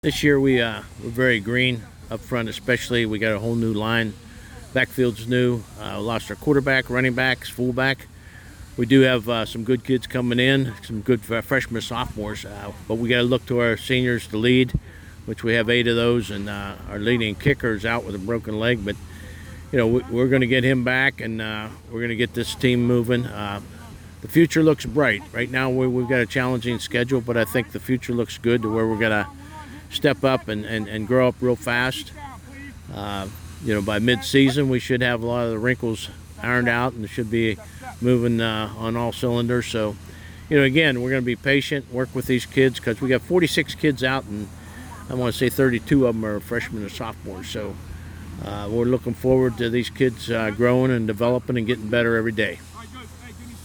Wh-central-coach-interview-7-10-23.m4a